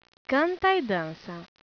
In this page, you can hear some brazilian portuguese words/phrases.